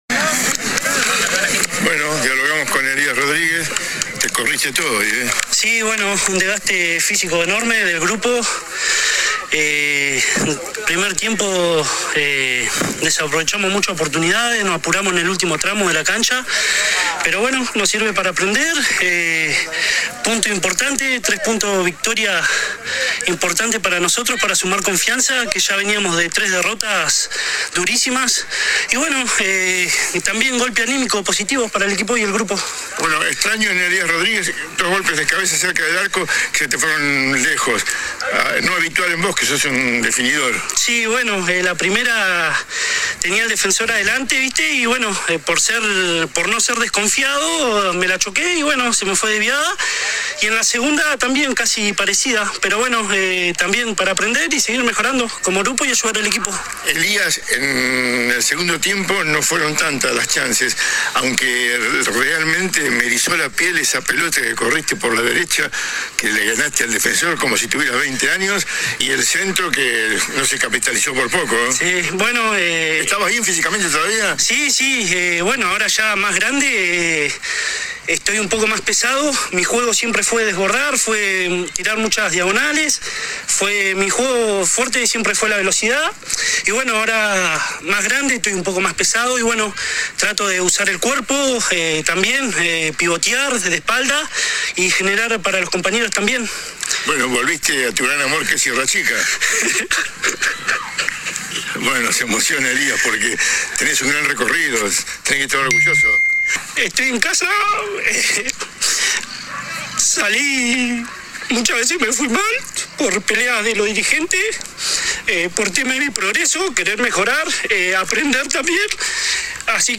No pudo sostener la emoción.
AUDIO DE LA ENTREVISTA